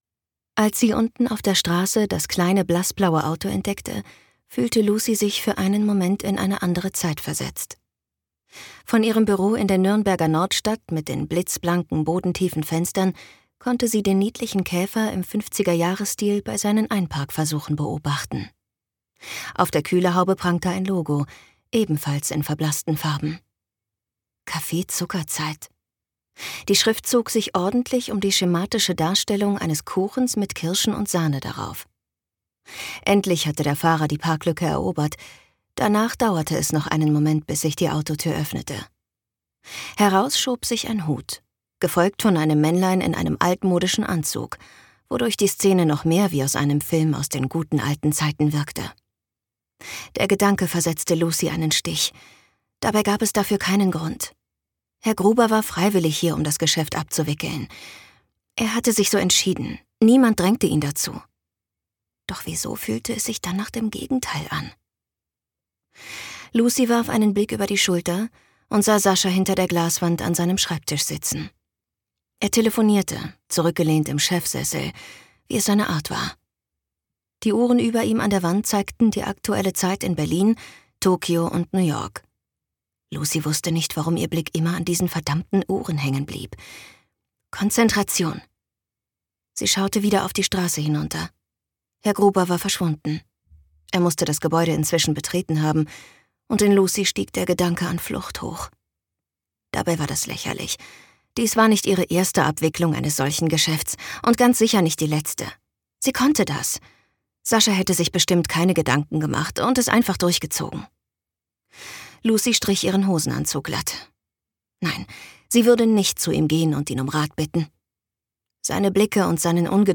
Ein zauberhaftes und warmherziges Wohlfühl-Hörbuch um Liebe und Neuanfänge, Zusammenhalt und den Mut, für sein Glück alles zu riskieren!
Gekürzt Autorisierte, d.h. von Autor:innen und / oder Verlagen freigegebene, bearbeitete Fassung.